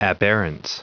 Prononciation du mot aberrance en anglais (fichier audio)
Prononciation du mot : aberrance